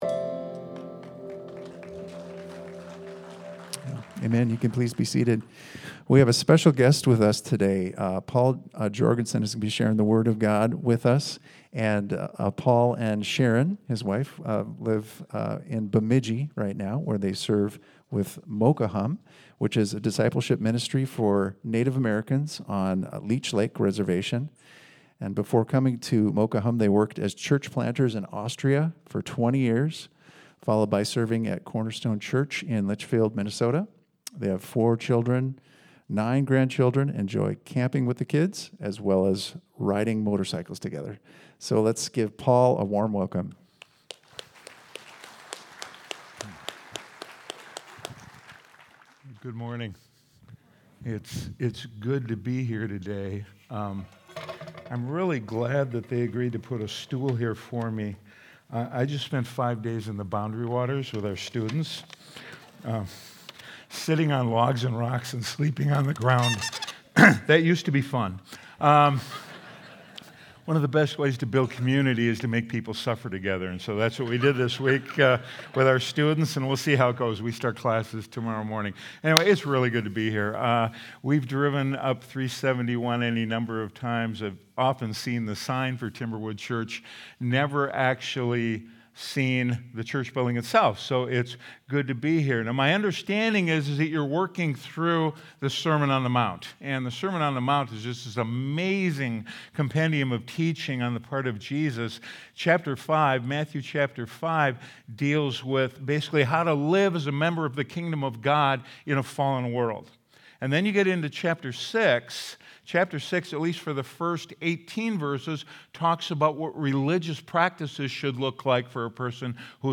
Sunday Sermon: 9-14-25